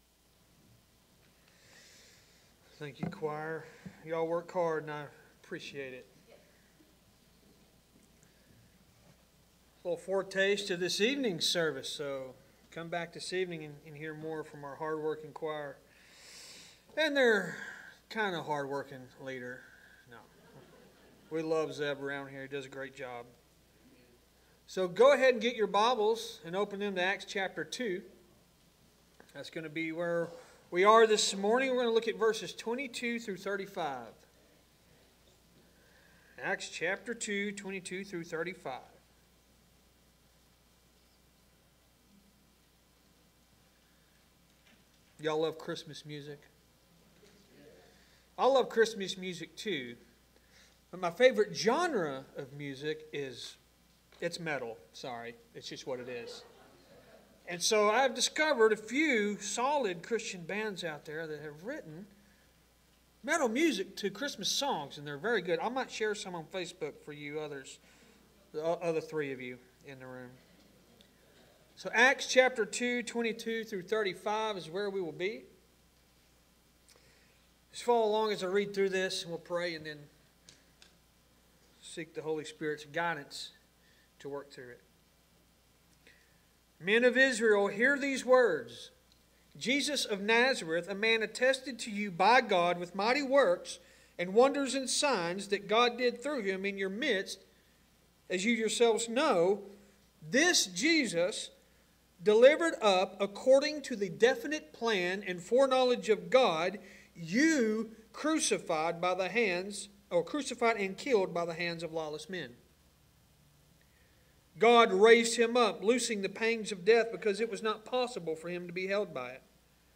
Sermons | Lake Athens Baptist Church